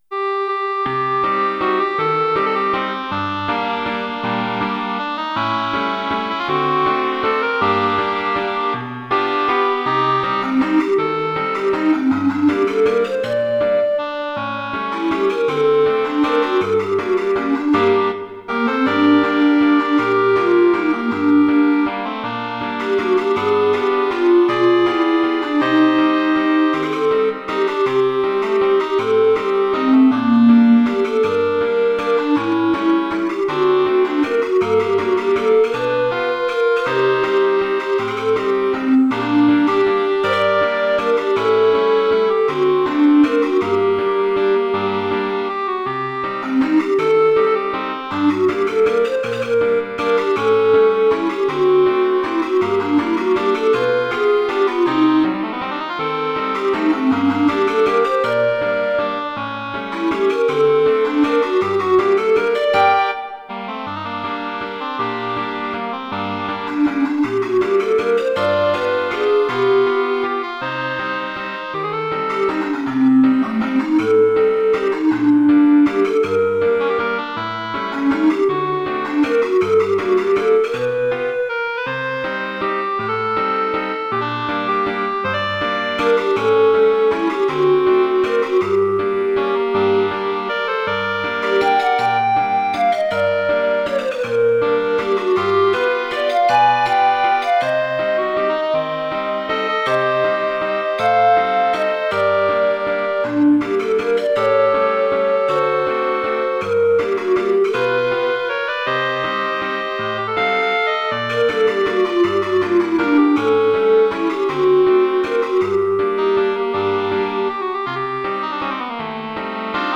ЗВУКОВЫЕ ИЛЛЮСТРАЦИИ НОТ
НЕСКОЛЬКО МЕЛОДИЙ ВЕЧЕРНЕГО НАСТРОЕНИЯ.